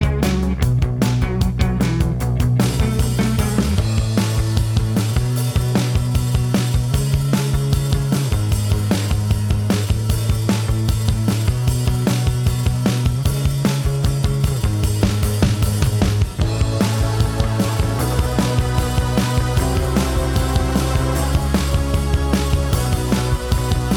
No Lead Guitar Pop (2010s) 4:49 Buy £1.50